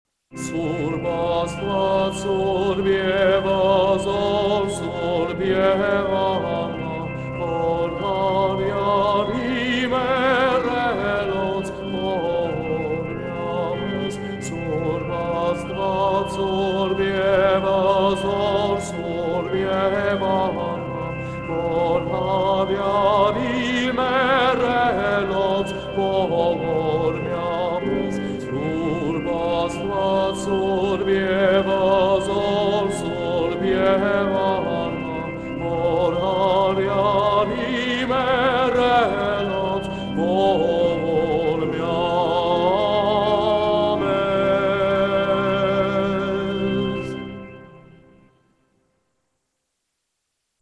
Click the video aboveWatch the Gospel Parade and “Soorp Asdvadz” during Badarak at St. Sahag Mesrob Church in RI. 2) How It Sounds Press the “Show Audio Player” button above and press play to listen to the entire hymn sung beautifully and faithfully.